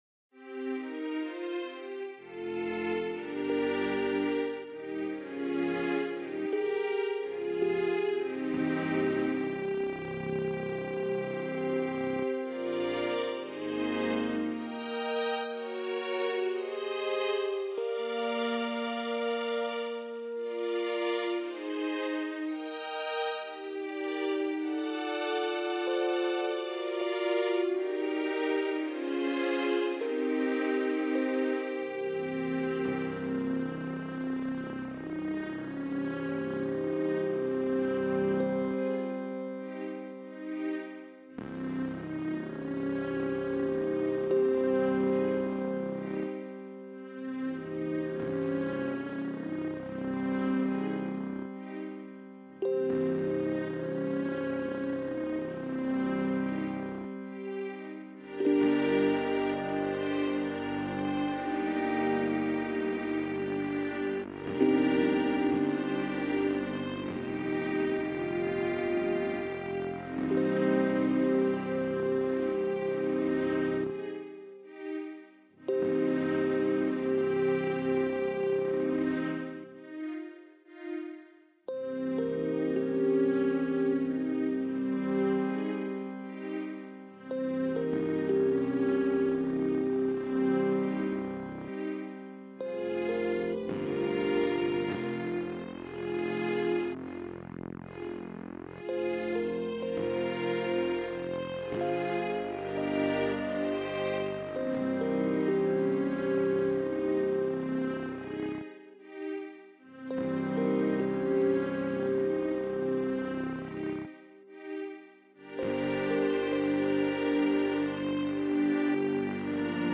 Slow tune with modulation